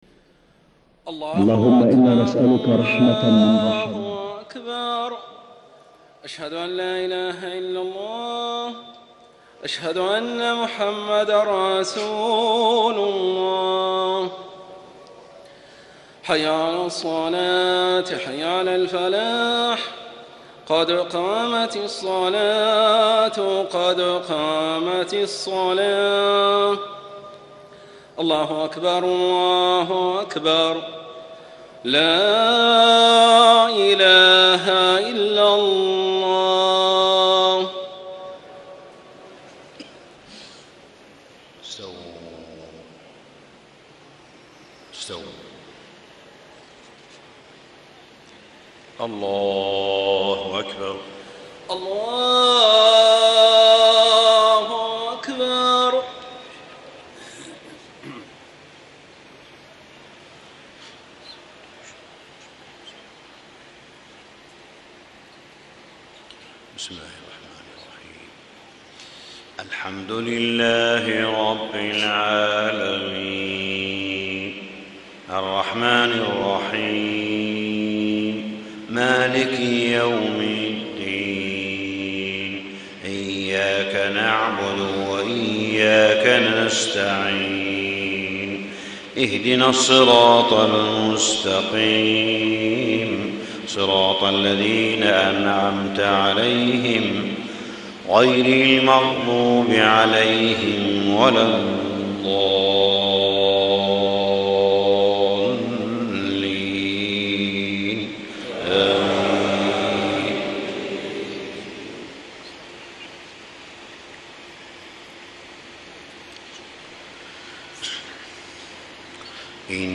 فجر 10 رمضان ١٤٣٥ من سورة ال عمران > 1435 🕋 > الفروض - تلاوات الحرمين